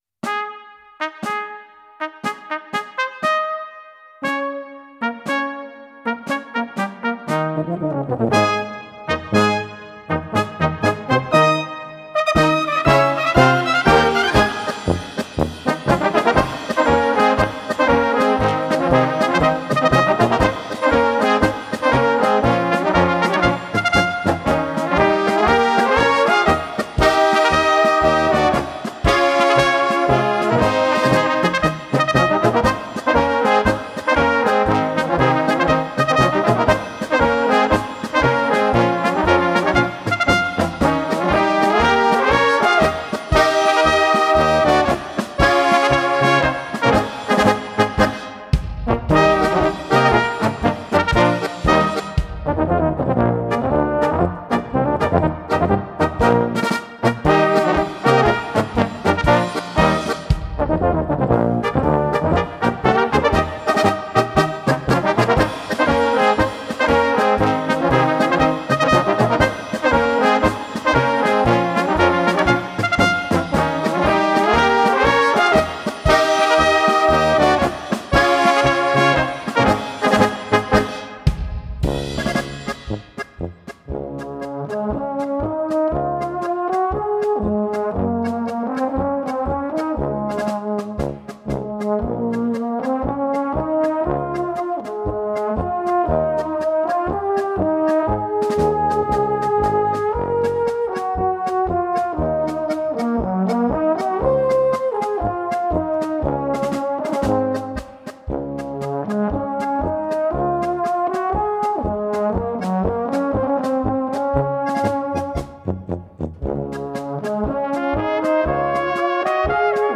Gattung: Marsch für kleine Besetzung
Besetzung: Kleine Blasmusik-Besetzung